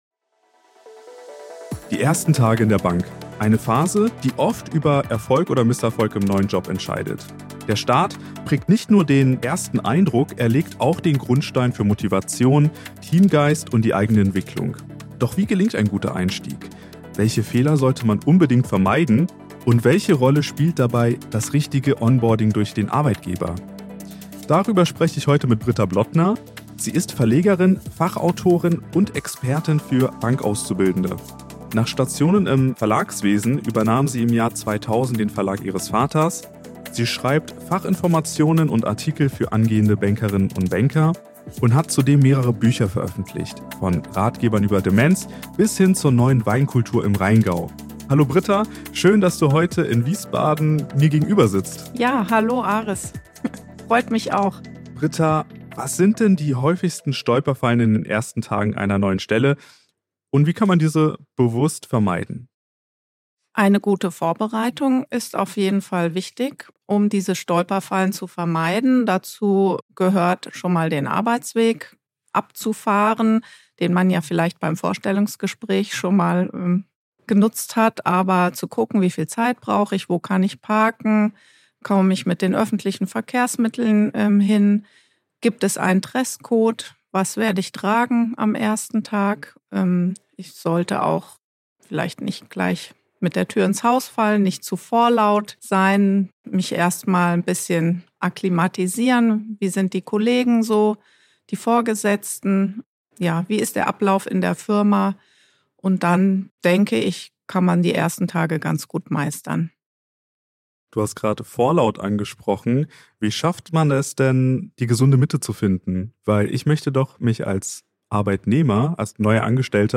Ein Gespräch voller Tipps für einen guten Start.